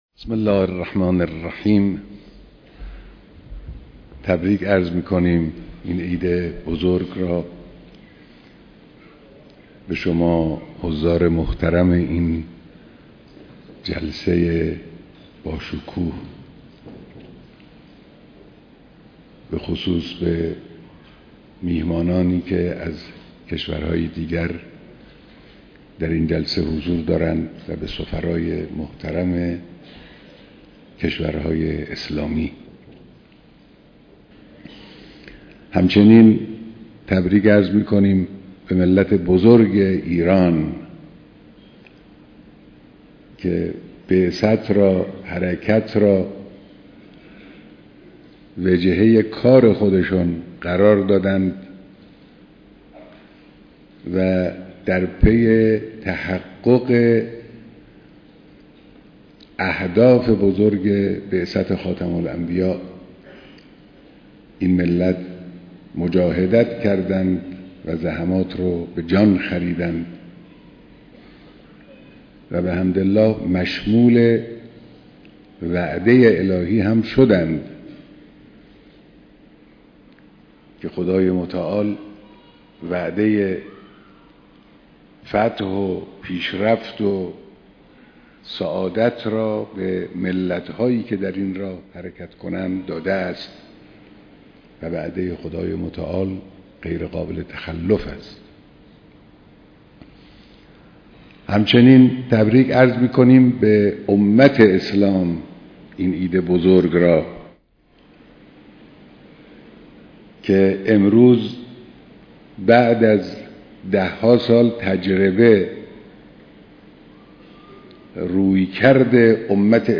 دریافت : mp4 40mb مدت : 18:46 صوت / بیانات رهبر معظم انقلاب در ديدار مسوولان نظام و سفرای كشورهای اسلامی 29 /خرداد/ 1391 دریافت : mp3 4mb مدت : 18:17